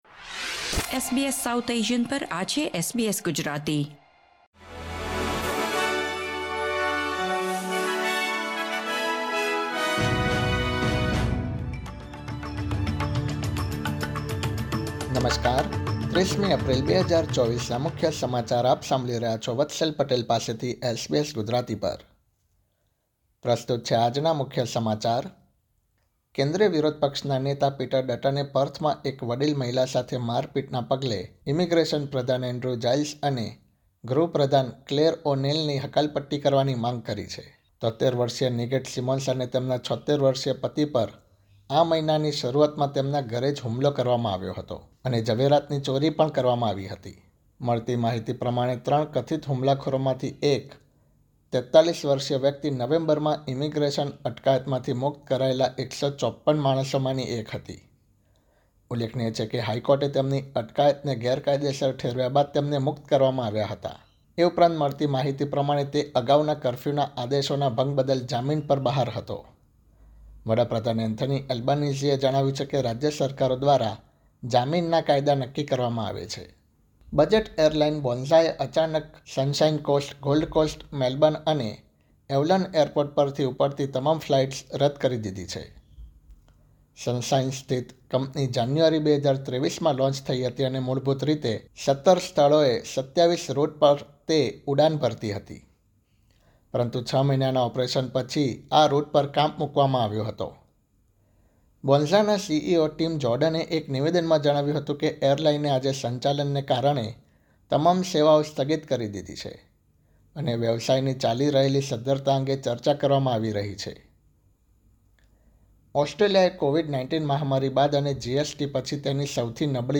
SBS Gujarati News Bulletin 30 April 2024